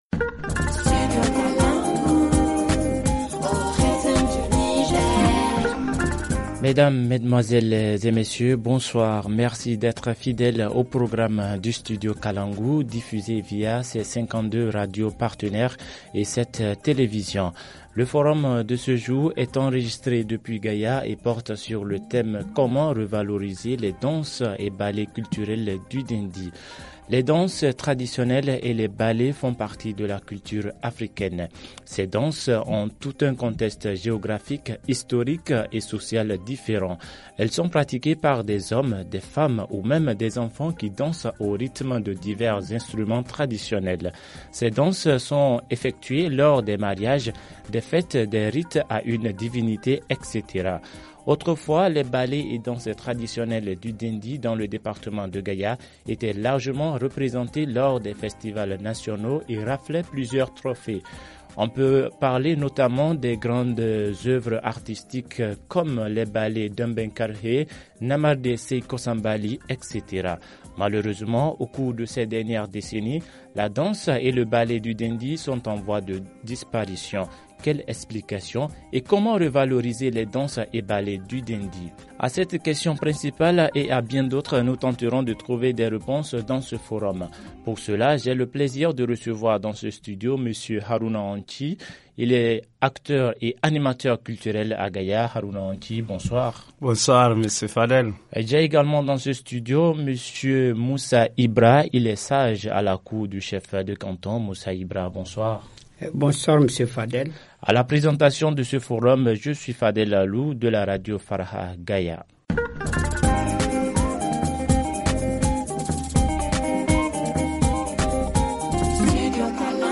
[Rediffusion] comment Revaloriser les danses et ballets culturels du Dendi ? - Studio Kalangou - Au rythme du Niger